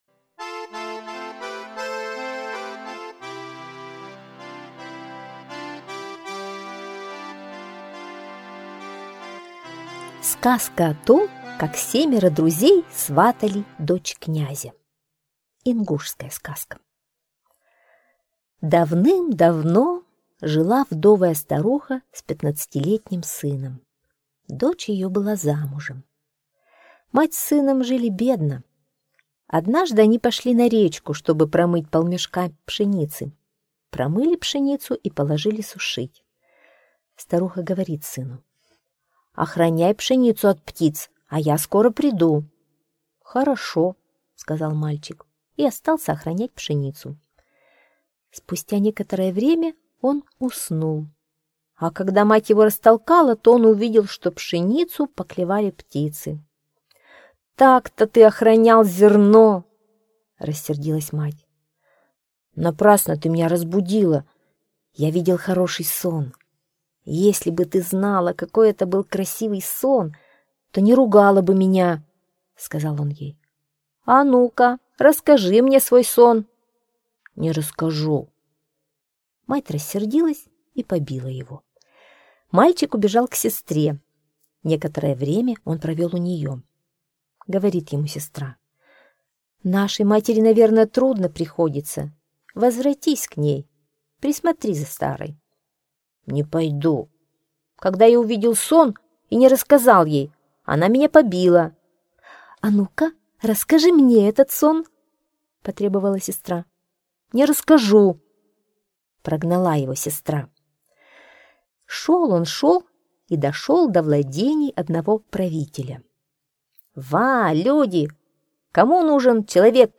Сказка о том, как семеро друзей сватали дочь князя - ингушская аудиосказка.